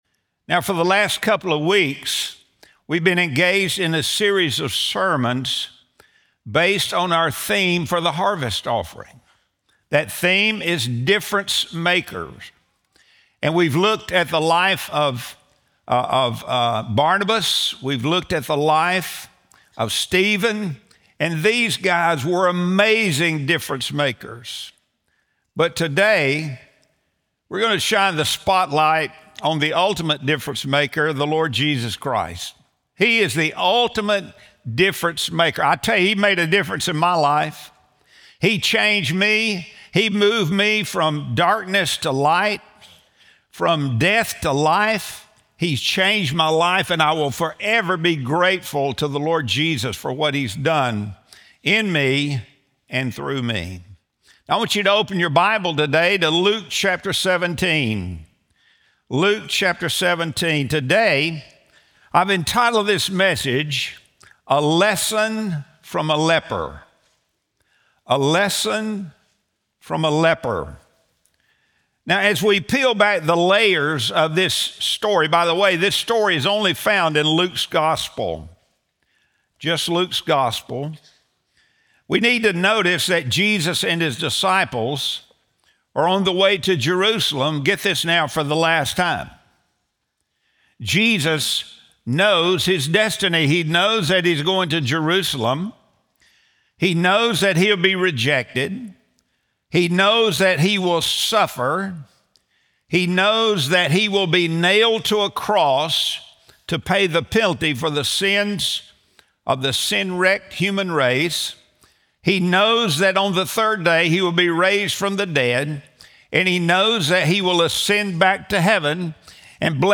Sunday Sermon | November 23, 2025